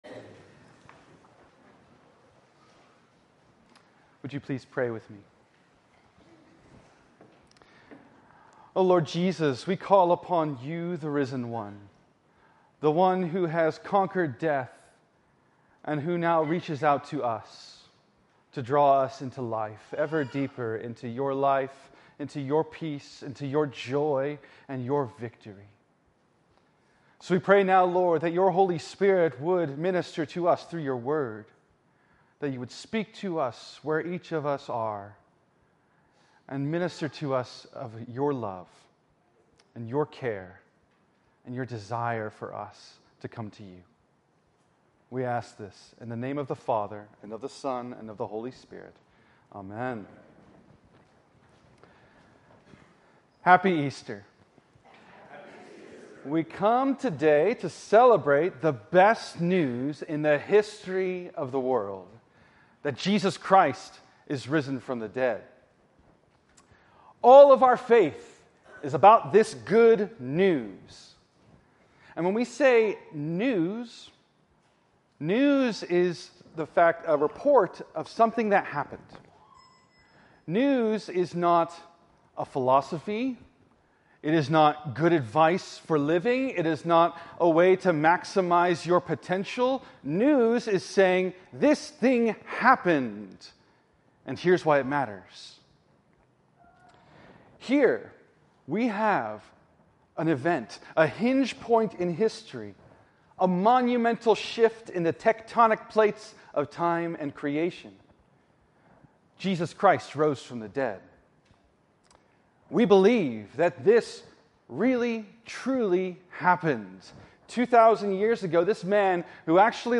In this sermon from Easter 2025